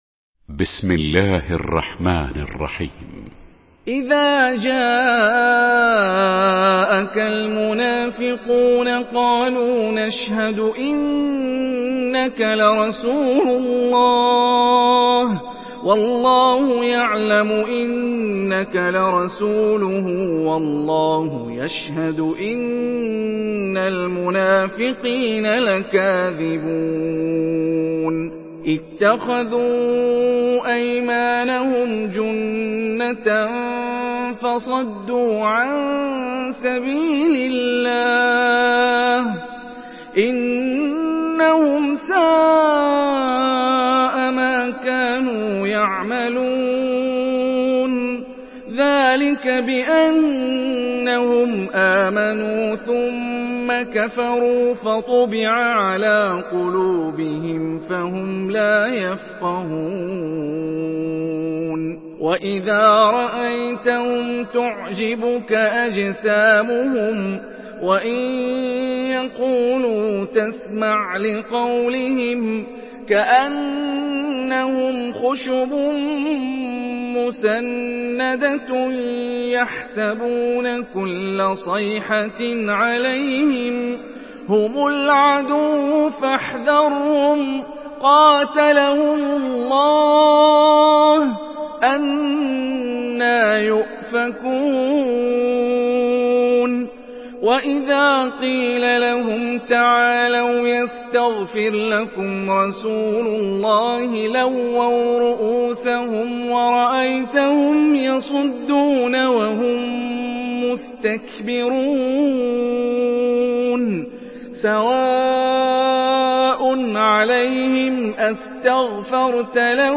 Surah আল-মুনাফিক্বূন MP3 by Hani Rifai in Hafs An Asim narration.
Murattal Hafs An Asim